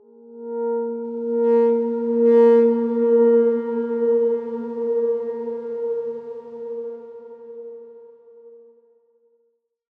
X_Darkswarm-A#3-f.wav